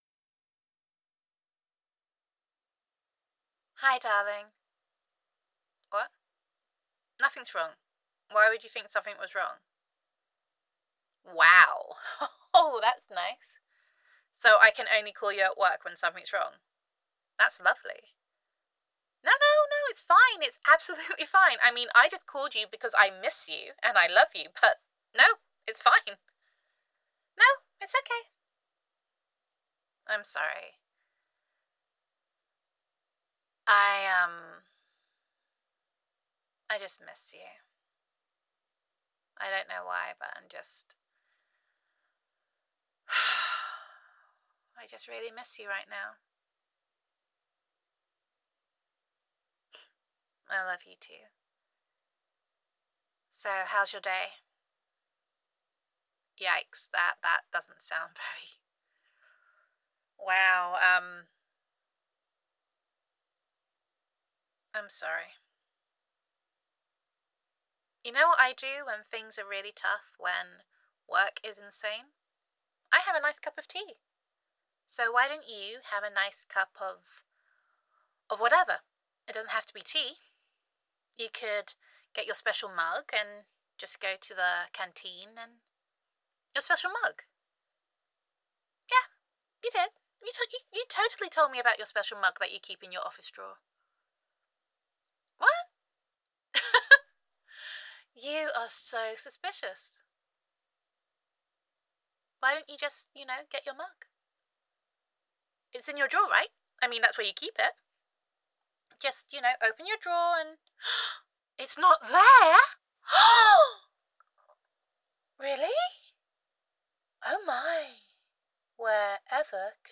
[F4A] I’m Getting Déjà Brew [Ransom Demand][Revenge][Play Bitch Games, Get Bitch Prizes][Teapot War Two][Girlfriend Voicemail][Gender Neutral][Your Girlfriend Calls You on a Rough Day at Work]